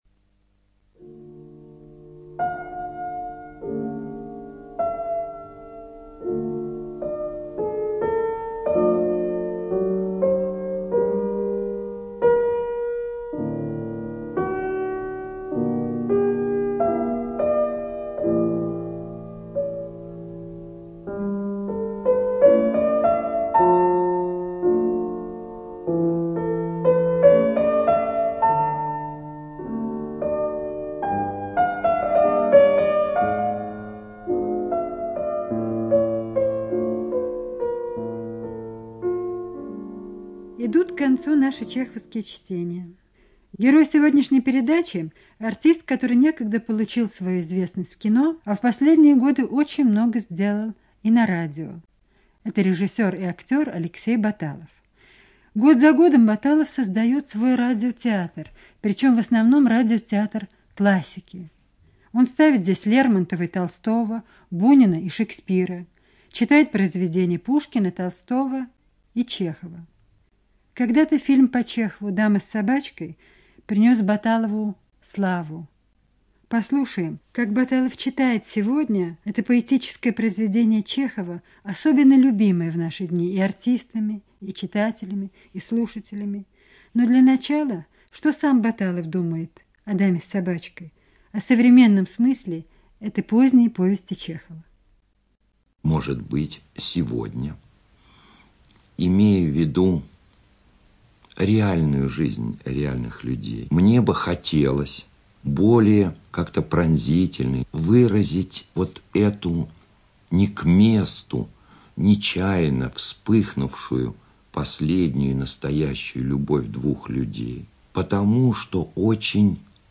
ЧитаетБаталов А.